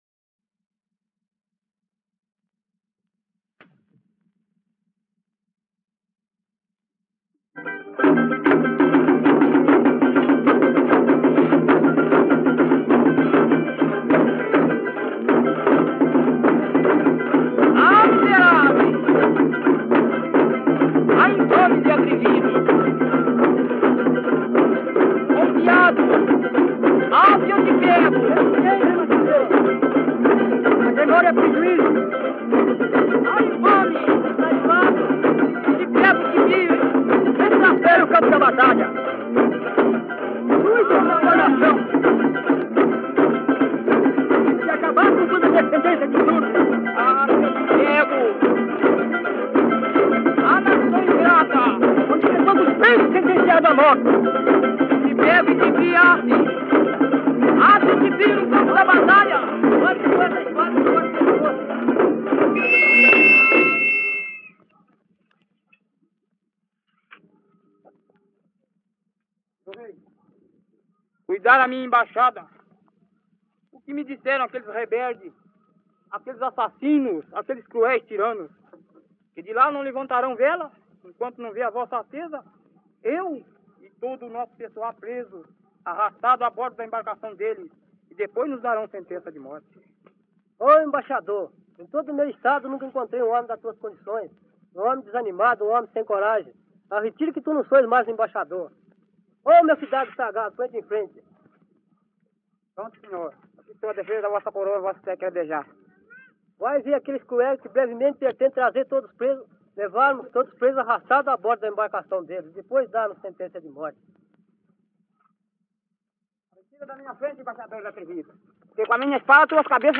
Fragmentos de embaixada de Congada (2)